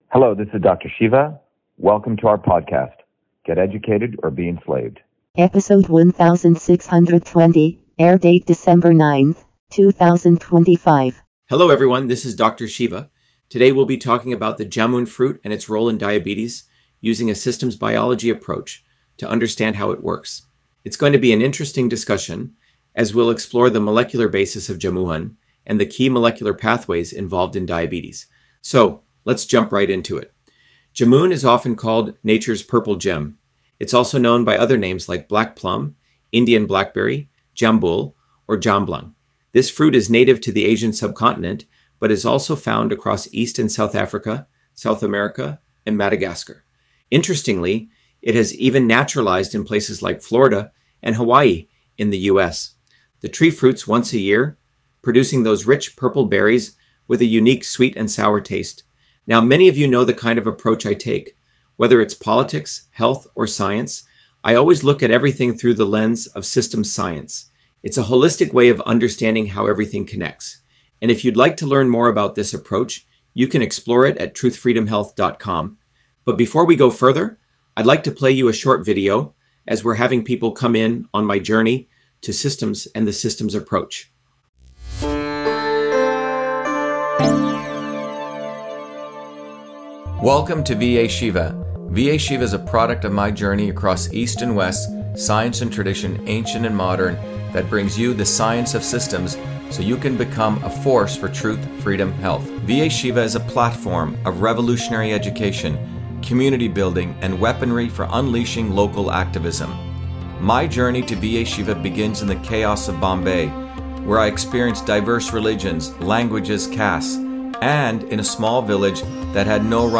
In this interview, Dr.SHIVA Ayyadurai, MIT PhD, Inventor of Email, Scientist, Engineer and Candidate for President, Talks about Jamun Fruit on Diabetes: A Whole Systems Approach